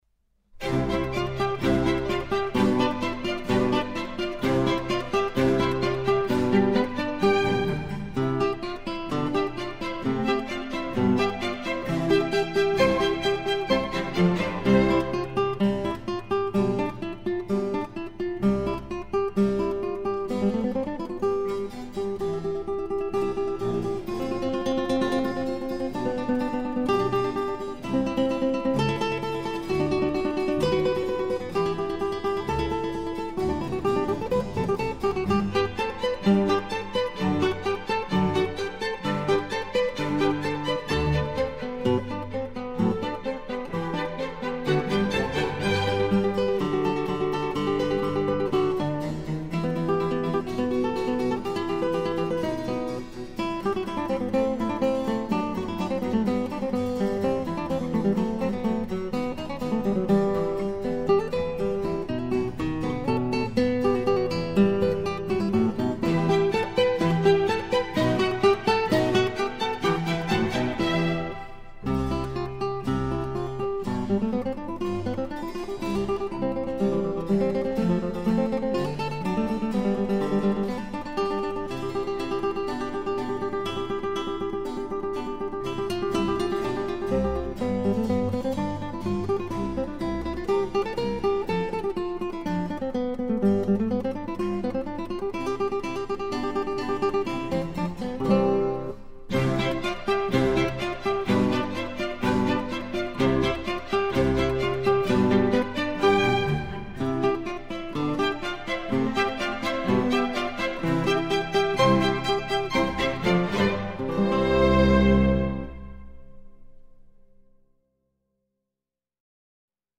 gitara
klawesyn